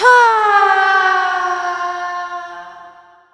falling1.wav